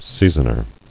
[sea·son·er]
Sea"son*er , n. One who, or that which, seasons, or gives a relish; a seasoning.